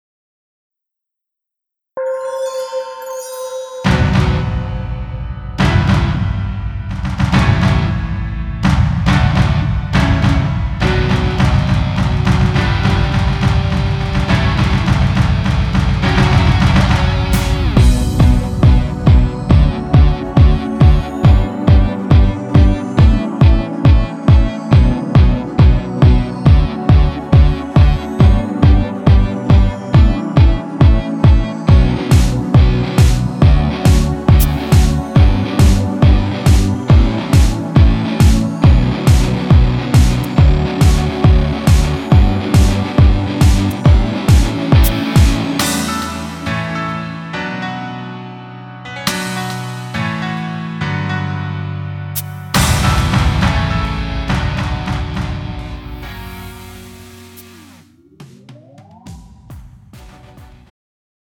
음정 남자키 3:24
장르 가요 구분 Pro MR
Pro MR은 공연, 축가, 전문 커버 등에 적합한 고음질 반주입니다.